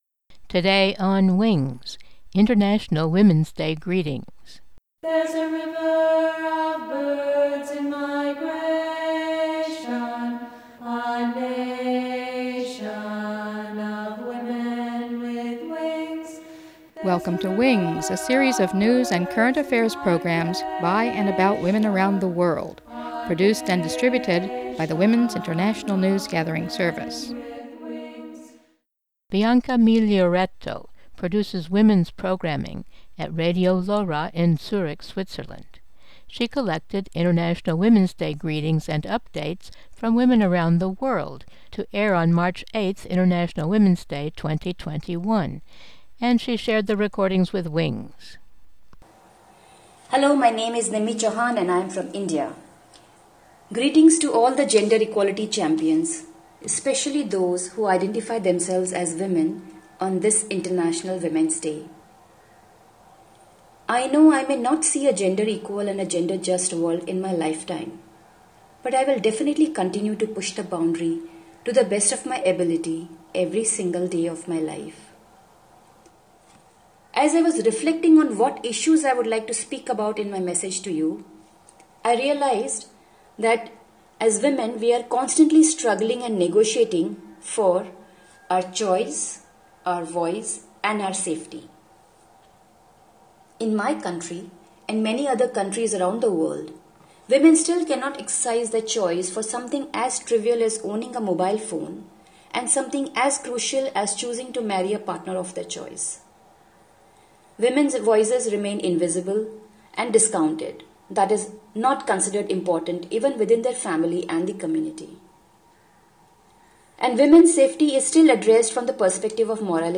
Greetings and updates from women around the world